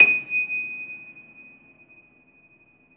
53c-pno23-D5.wav